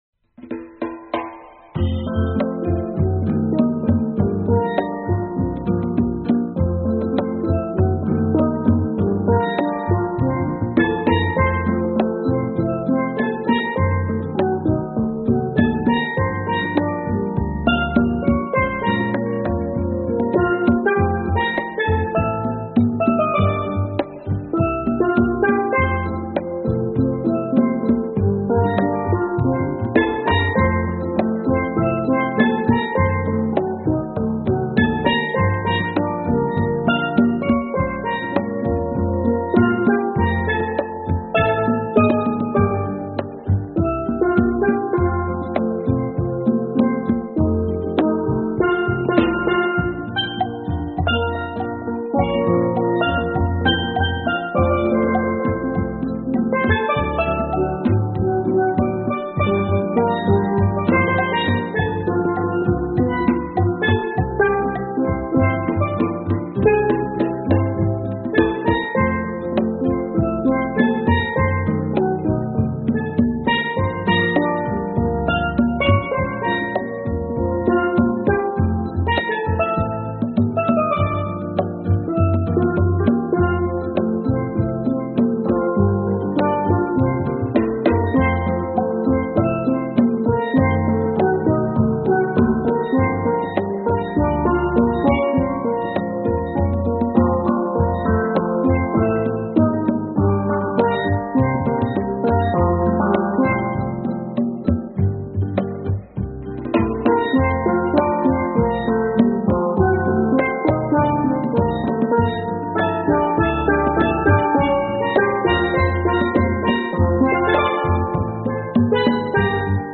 Steel Drum Wedding Music: